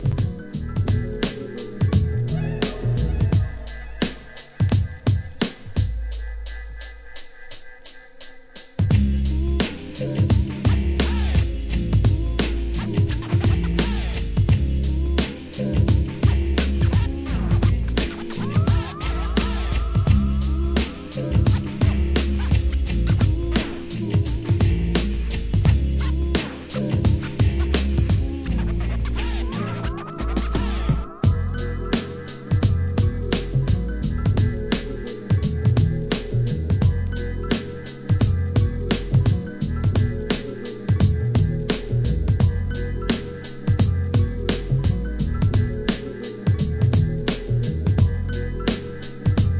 ブレイクビーツ